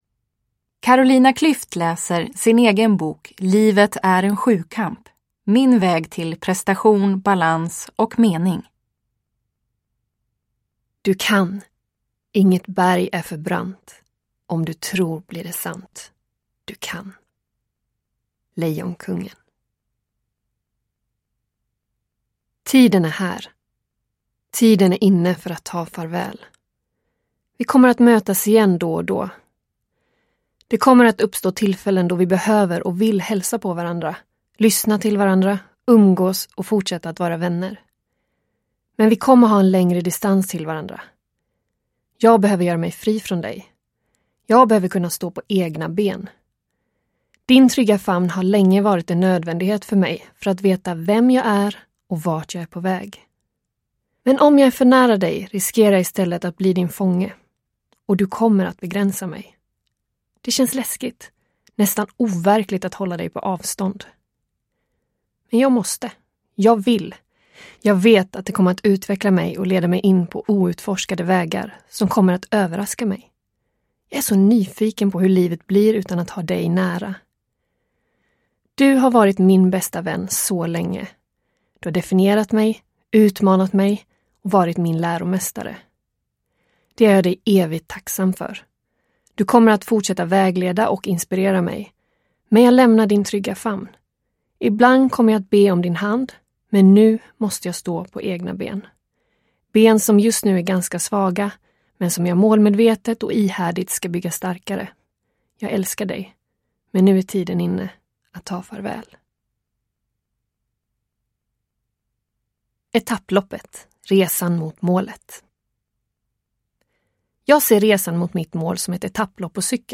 Uppläsare: Carolina Klüft